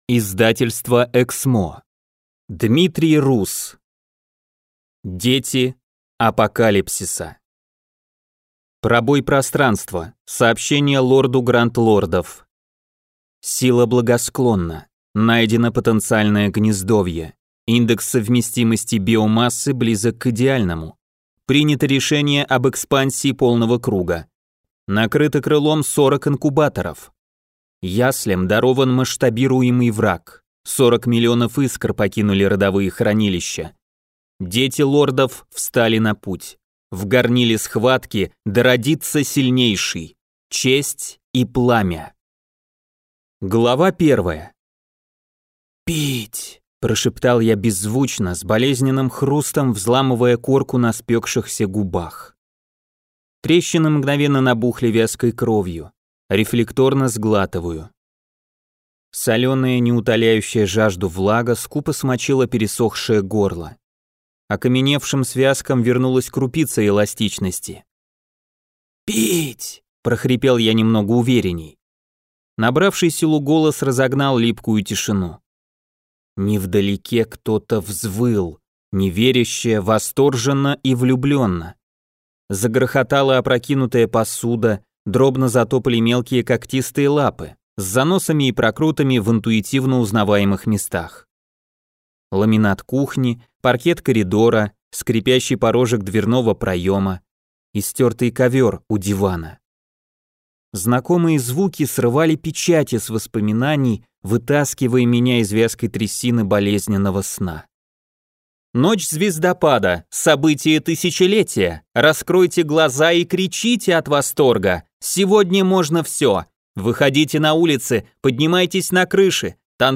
Аудиокнига Дети апокалипсиса | Библиотека аудиокниг